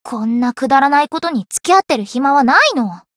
灵魂潮汐-神纳木弁天-互动-不耐烦的反馈2.ogg